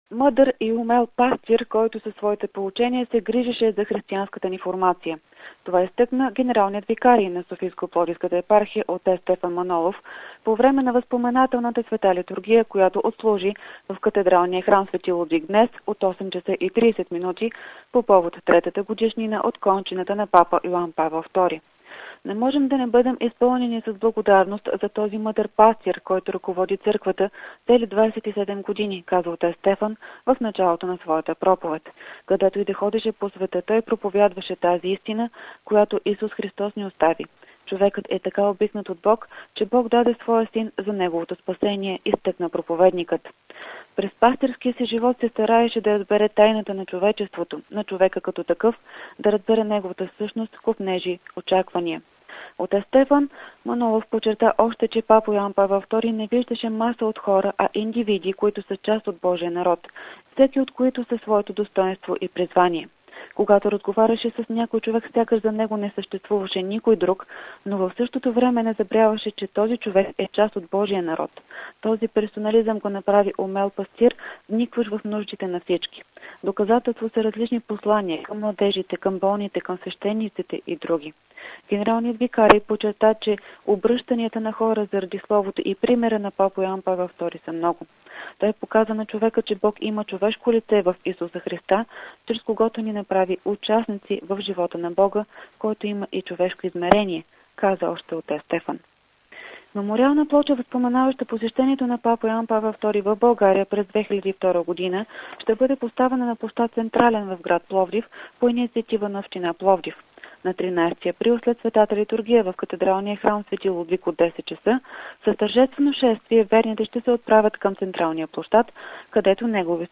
Кореспонденция